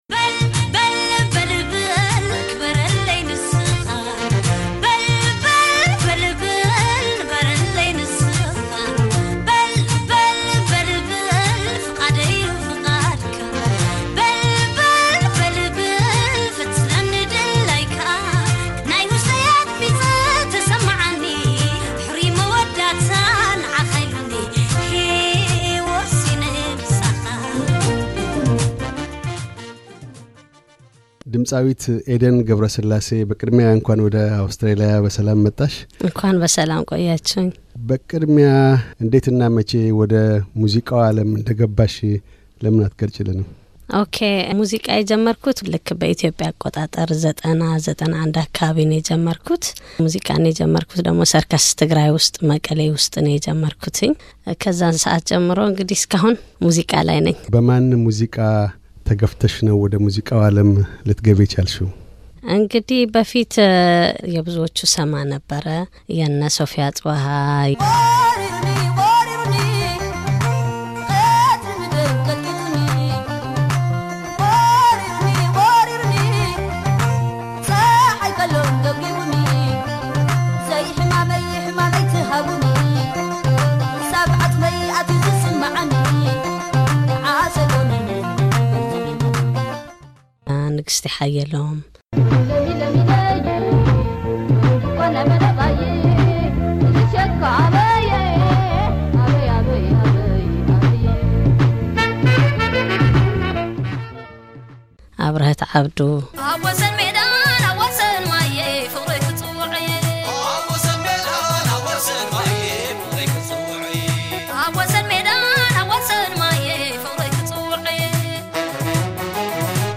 ቃለ ምልልስ።